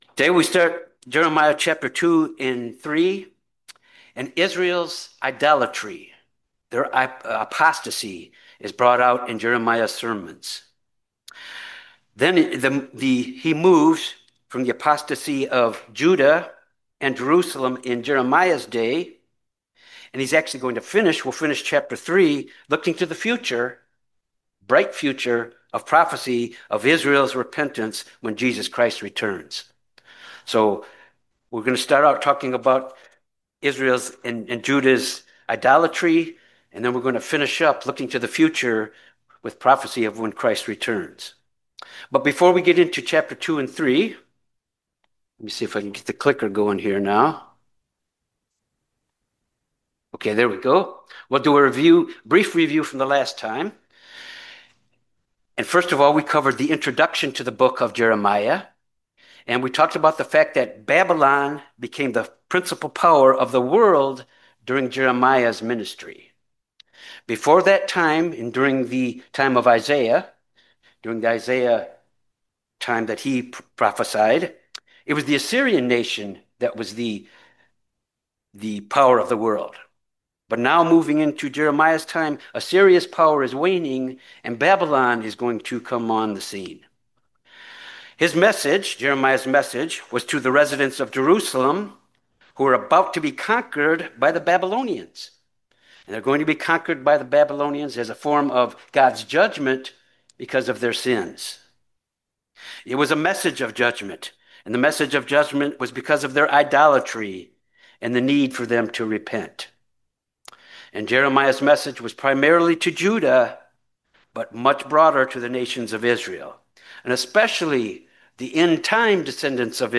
This Bible Study examines chapter 2-3 of the Book of Jeremiah